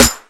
Snare19.wav